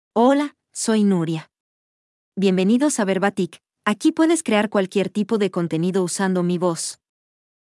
FemaleSpanish (Mexico)
Nuria — Female Spanish AI voice
Voice sample
Listen to Nuria's female Spanish voice.
Nuria delivers clear pronunciation with authentic Mexico Spanish intonation, making your content sound professionally produced.